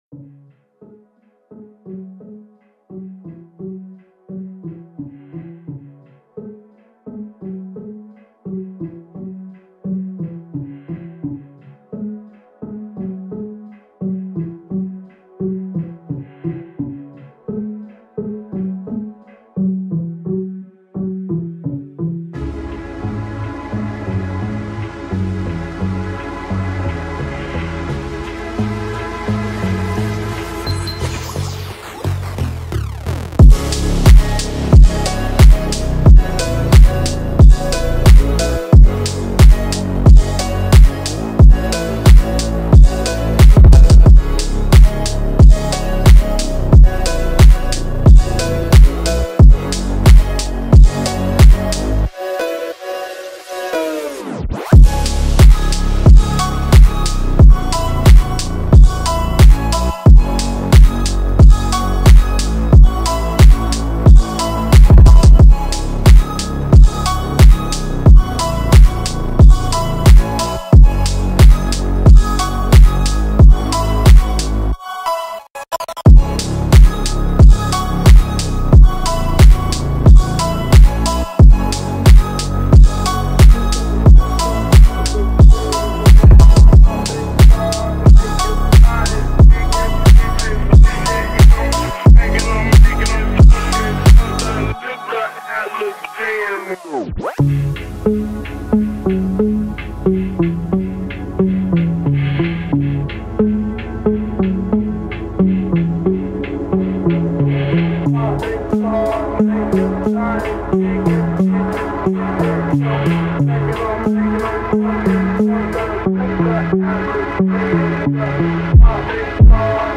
• Качество: 320 kbps, Stereo
Phonk remix